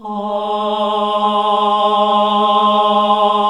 AAH G#1 -R.wav